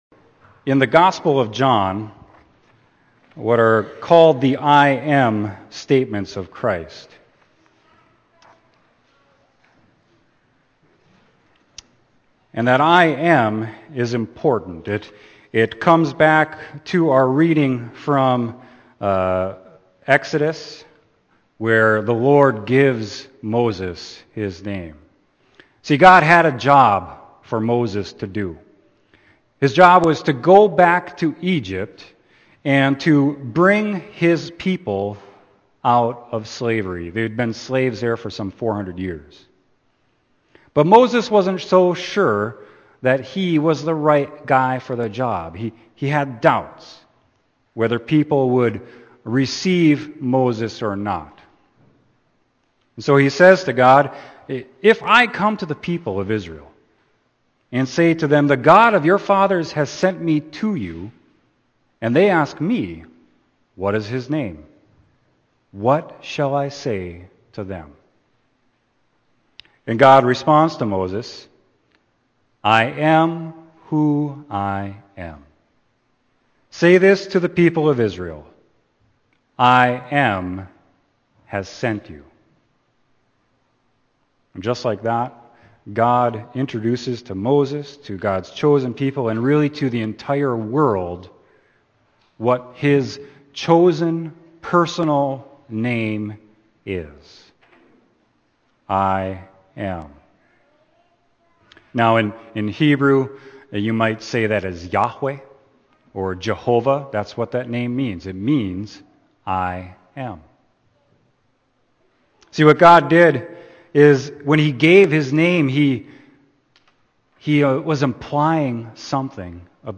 Sermon: John 8.48-59